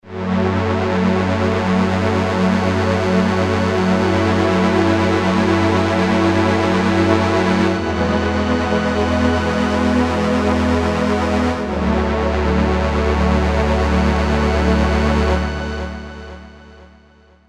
raw pad (+reverb)